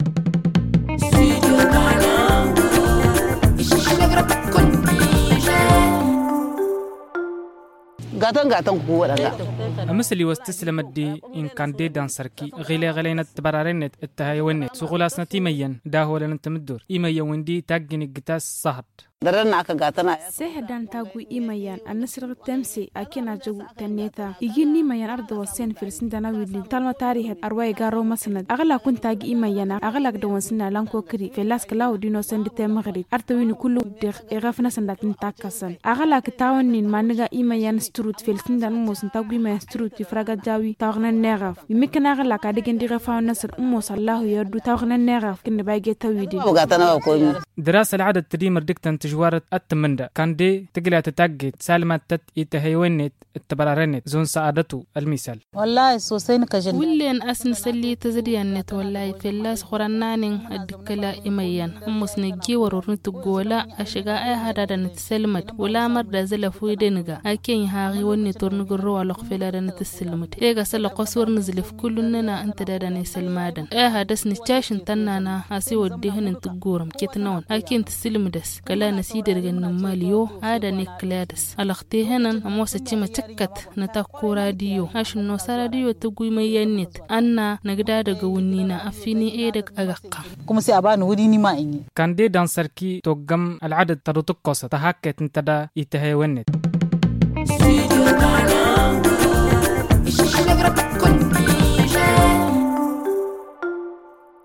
Le magazine en tamasheq